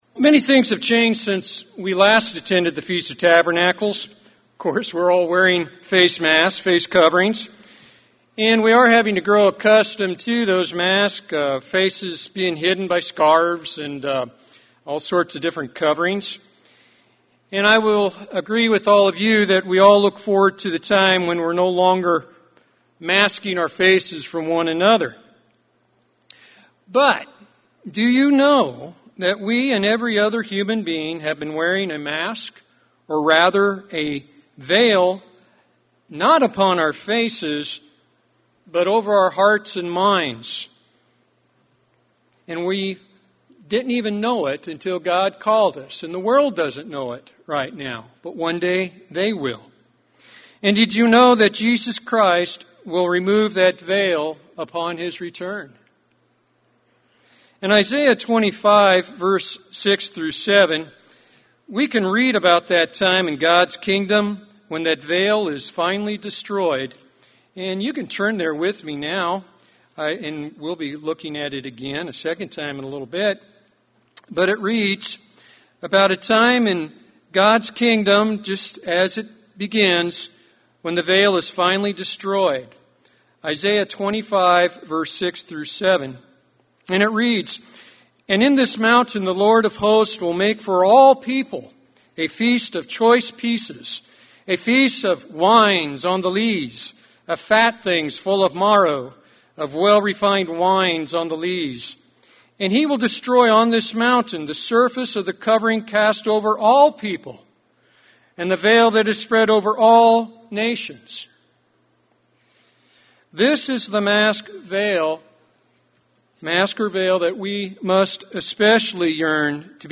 This sermon was given at the Branson, Missouri 2020 Feast site.